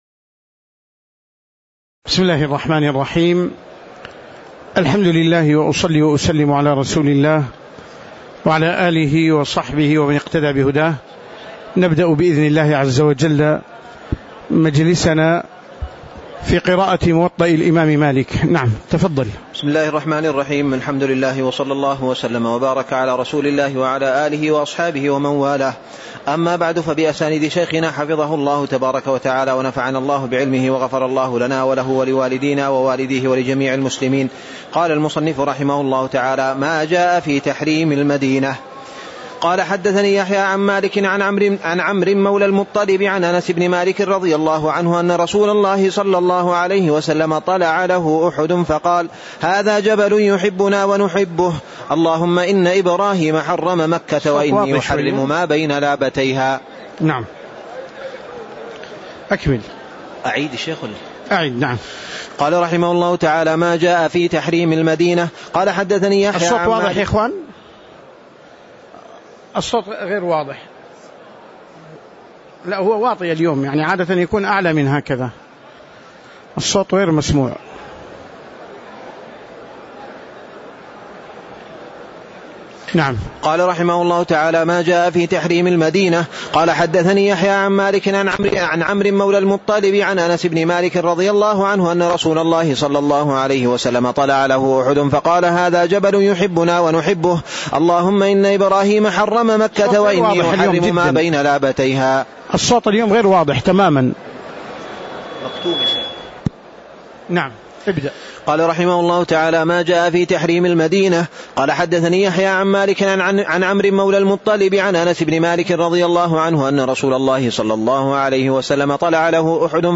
تاريخ النشر ١٠ رمضان ١٤٣٧ هـ المكان: المسجد النبوي الشيخ